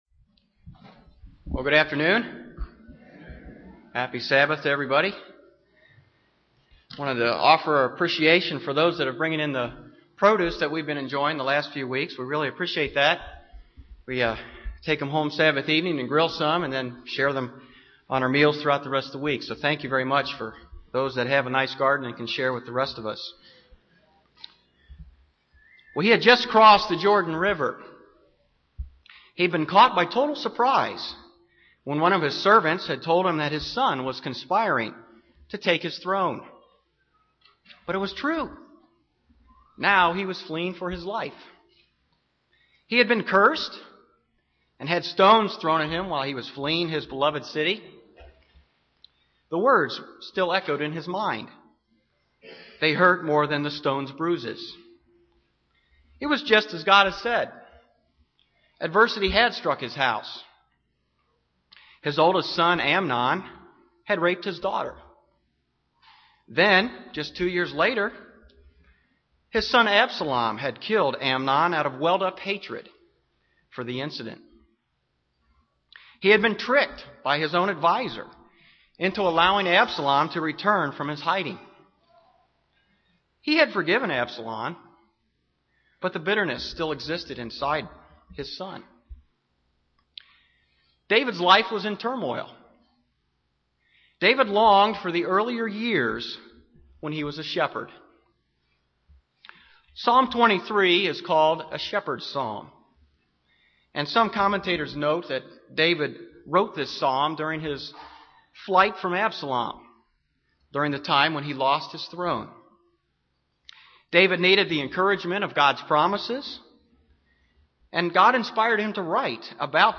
Given in Indianapolis, IN
UCG Sermon Studying the bible?